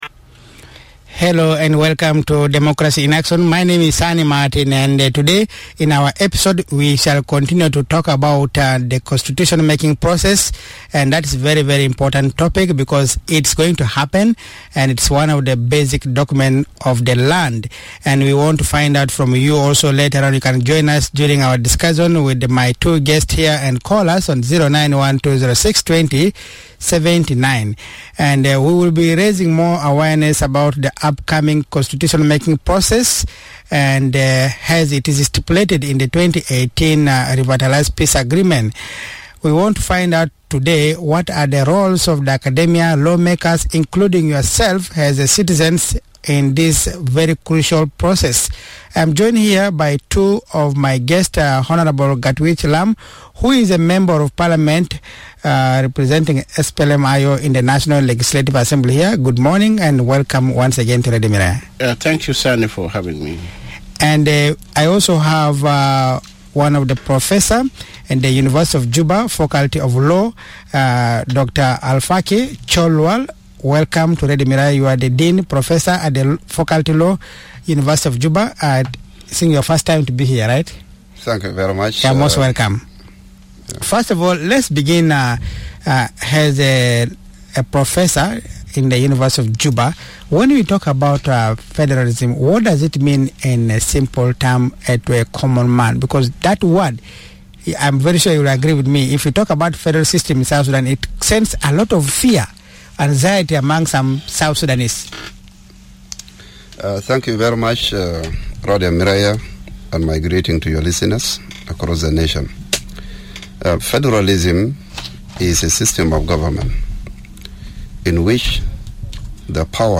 In this episode, of Democracy in Action, our guests describe the process of enacting a federal system in the governance of South Sudan. They speak on the level of public participation in the conception of the appropriate federal system for the country.